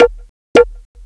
PERC LOOP6-R.wav